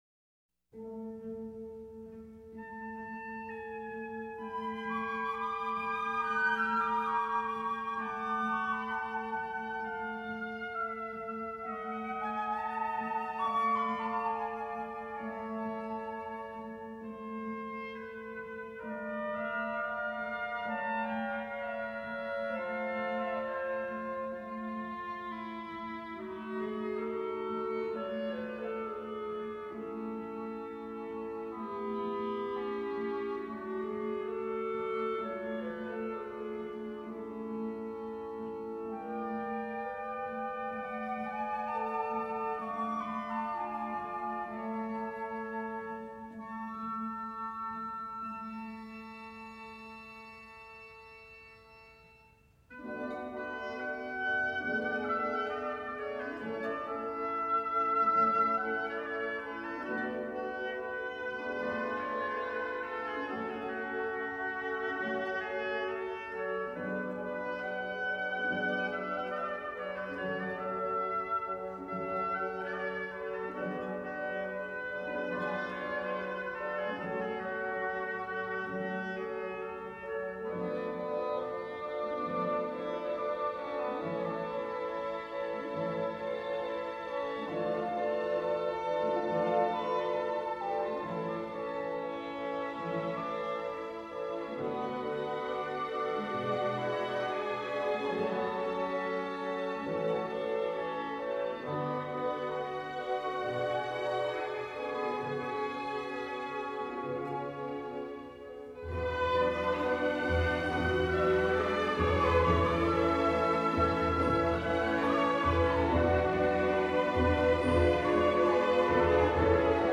Relaxing-with-the-Classics-Disc-2-05-Prince-Igor-opera-Polovstian-Dance-No.-17-Excerpt-Classical.mp3